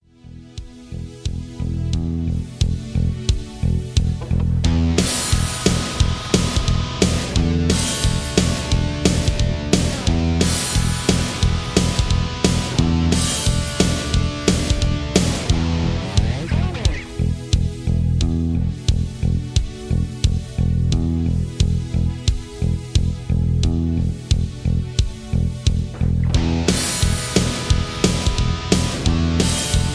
(Version-1) Karaoke MP3 Backing Tracks
Just Plain & Simply "GREAT MUSIC" (No Lyrics).